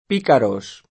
p&karoS] — italianizz. picaro [p&karo] (pl.